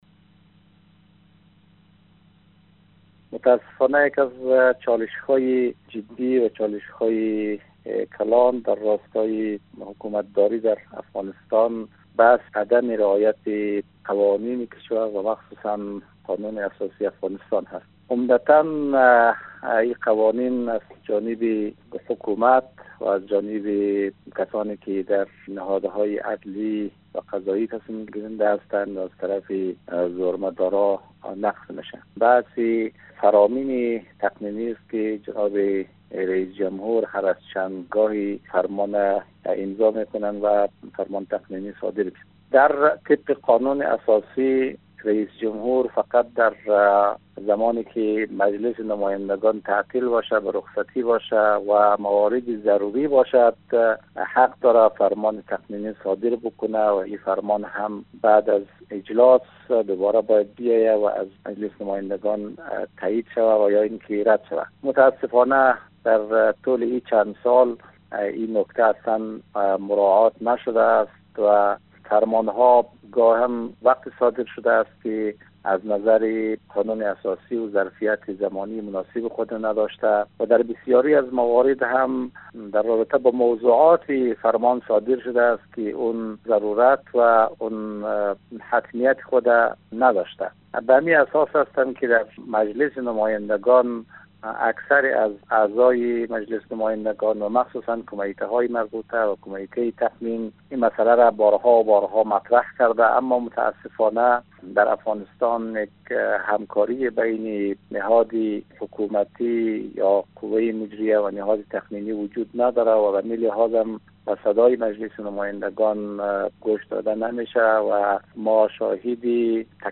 محمد علی اخلاقی نماینده مردم غزنی در مجلس نمایندگان، در گفت و گو با رادیو دری، در درباره تخطی‌های قانونی اشرف غنی رئیس جمهور افغانستان در مورد فرمان های تقنینی گفت: فرمان های تقنینی اشرف غنی ناقض قانون اساسی و اختیارات اختصاصی مجلس است و این قوانین همیشه یا از سوی حکومت و یا از سوی کسانی که در نهادهای قضایی تصمیم گیرنده هستند و یا از طرف زورمندان نقض می شود.